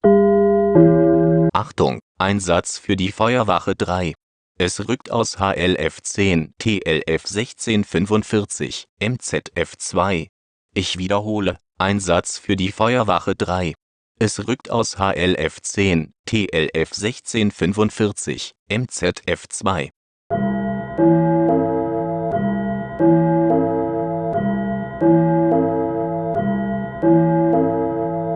FFV Gong - Alarmmonitor - Feuerwehr Vettelschoß
FFV-Gong-Alarmmonitor.wav